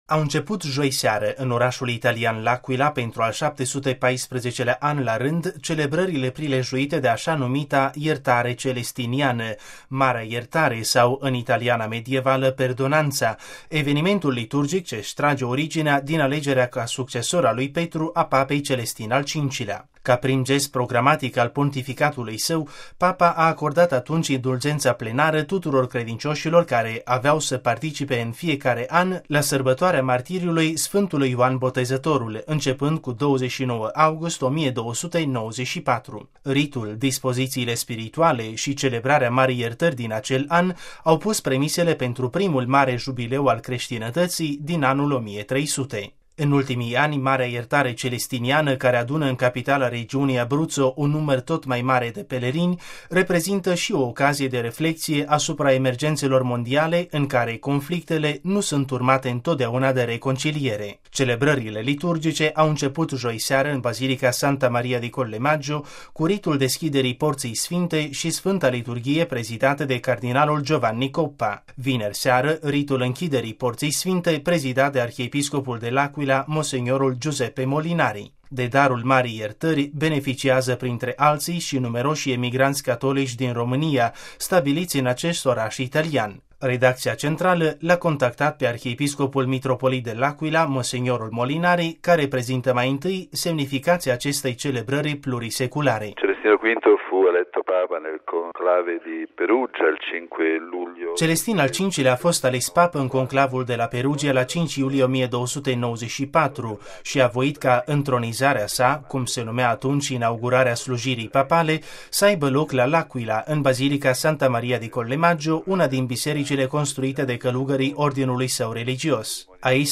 Redacţia centrală l-a contactat pe arhiepiscopul mitropolit de L'Aquila, mons. Molinari, care prezintă mai întâi semnificaţia acestei celebrări pluriseculare: